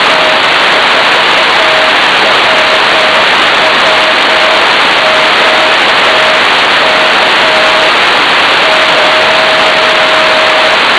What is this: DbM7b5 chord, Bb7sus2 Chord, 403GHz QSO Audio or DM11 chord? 403GHz QSO Audio